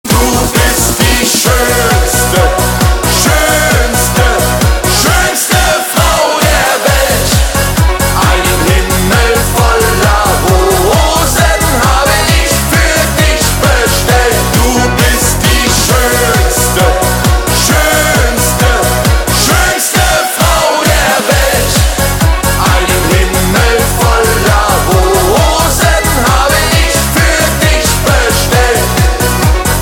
Gattung: Solo für Gesang und Blasorchester
Besetzung: Blasorchester
Der neue Party- und Festzeltkracher
Tonart: As-Dur